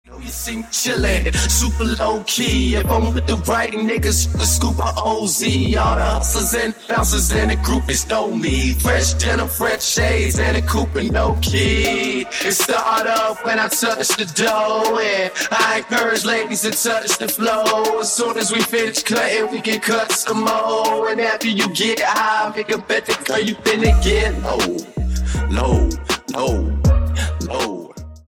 рэп
хип-хоп
басы